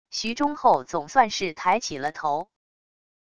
徐忠厚总算是抬起了头wav音频生成系统WAV Audio Player